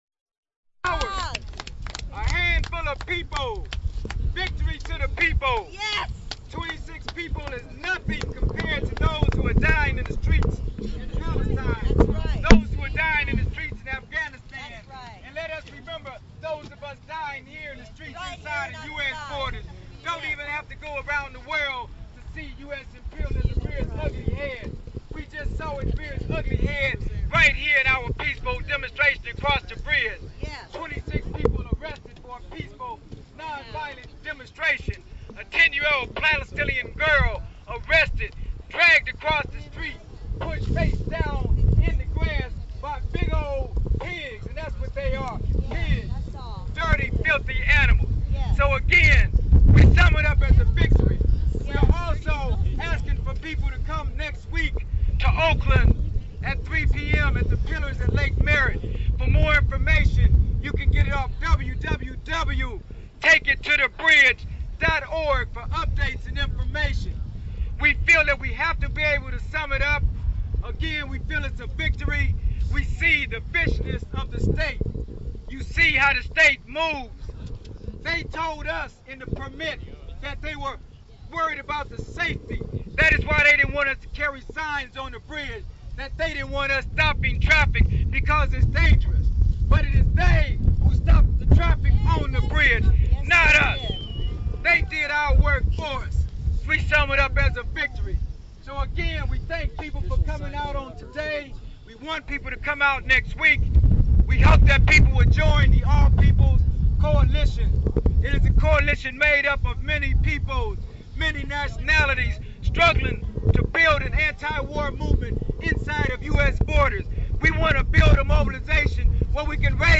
Wrap up speeches recorded after Take It To the Bridge.